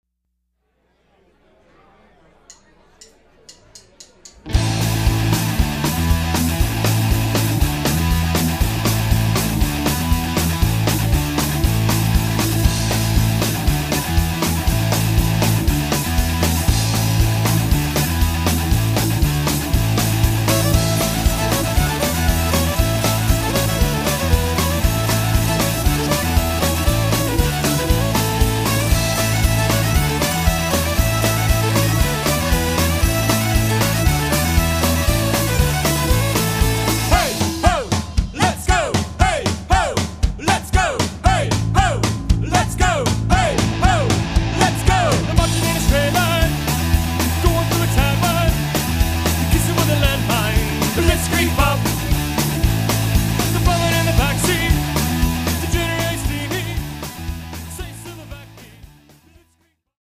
A bit of fiddle-punk
Celtic-rock band